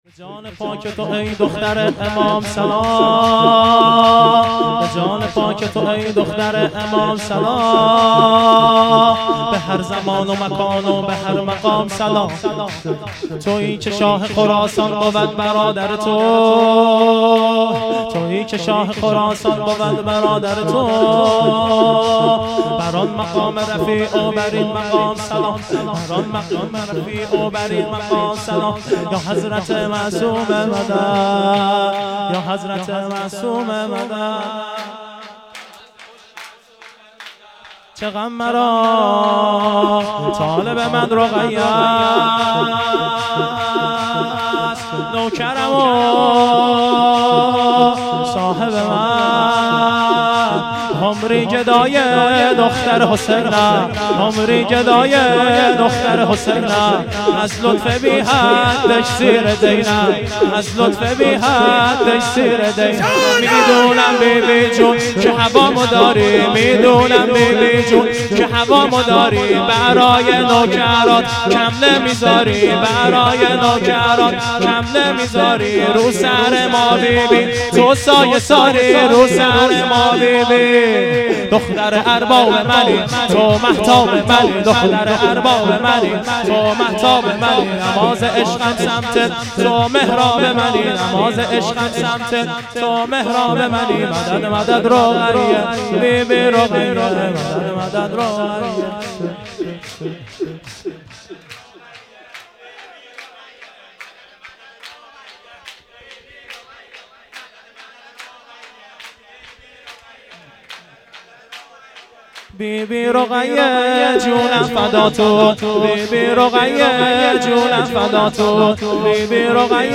شور
هیئت عاشقان ثارالله (ع)